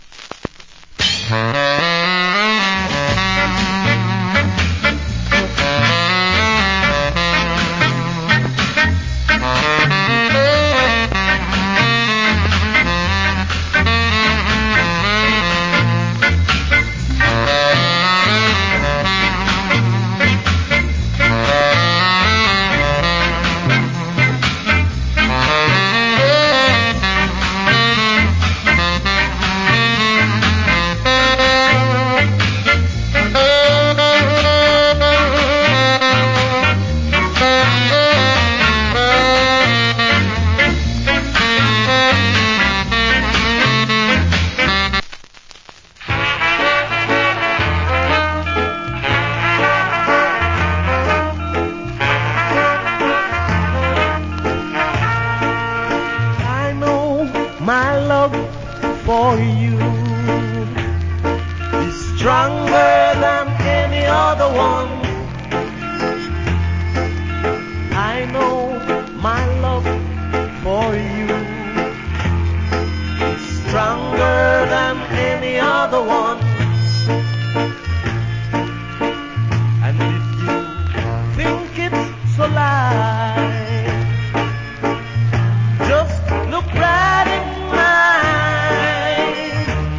Great Ska Inst.